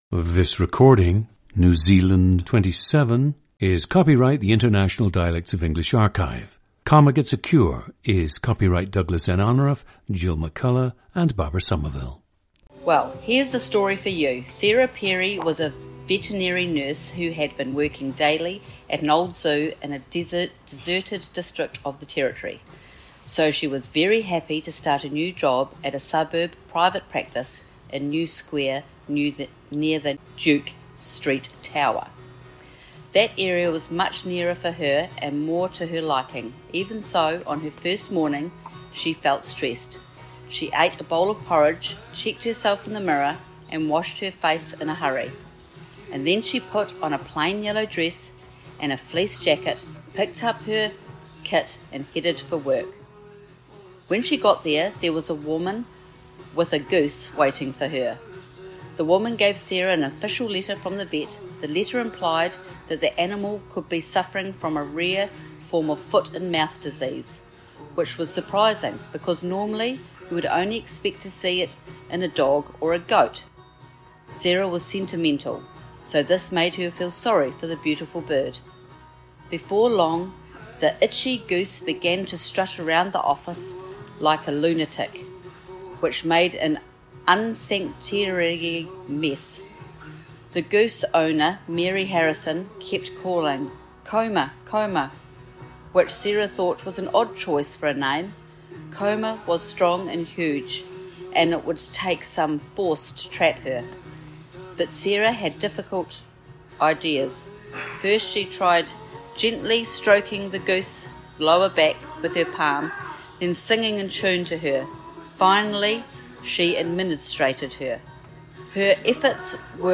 PLACE OF BIRTH: Christchurch, New Zealand
GENDER: female
So her pronunciations of certain words might reflect that difficulty rather than her dialect.
The recordings average four minutes in length and feature both the reading of one of two standard passages, and some unscripted speech.